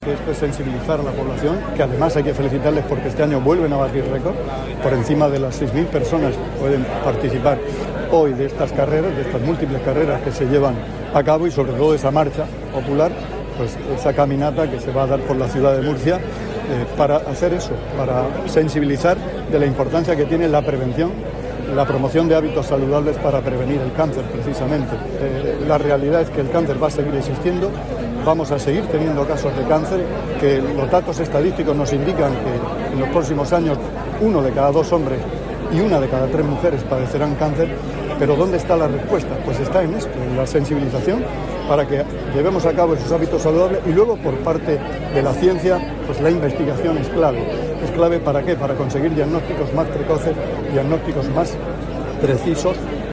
Declaraciones del consejero de Salud, Juan José Pedreño, sobre la carrera organizada por la AECC en la ciudad de Murcia.